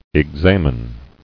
[ex·a·men]